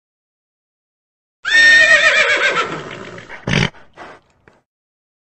Horse Sound Effect 8 H299d Mu Z8 M (audio/mpeg)
HORSE